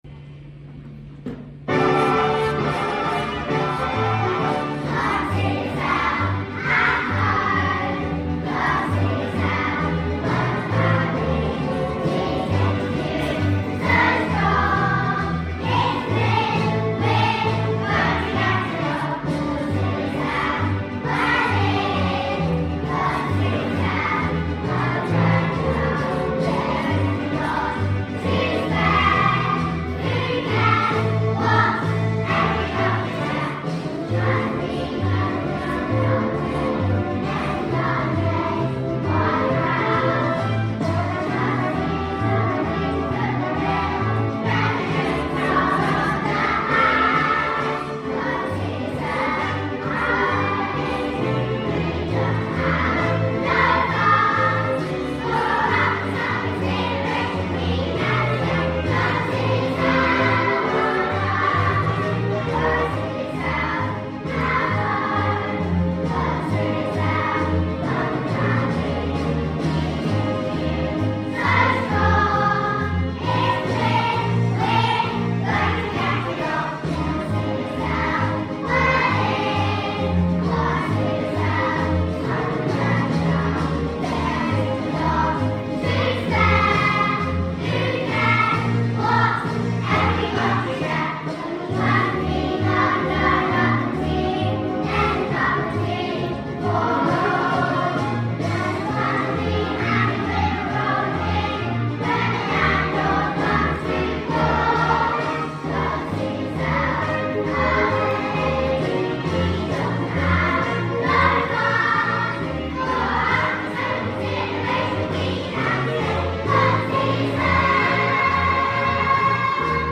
Consider Yourself | Junior Choir